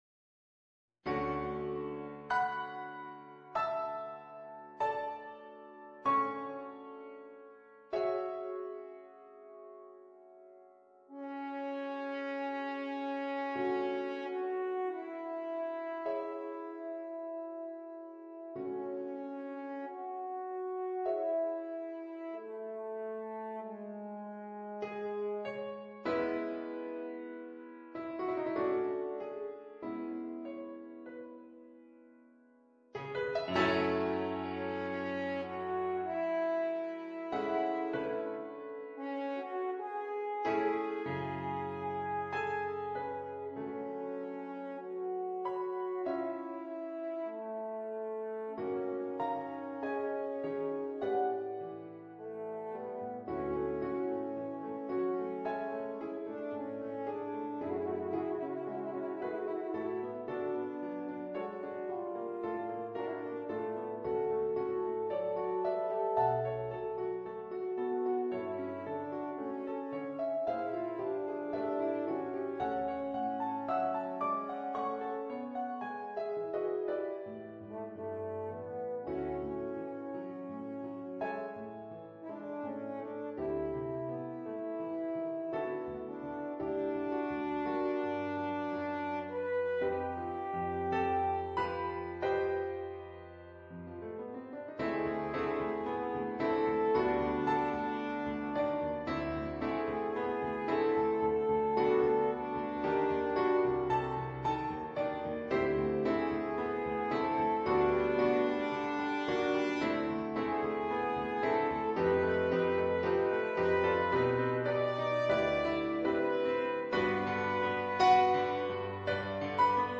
per corno e pianoforte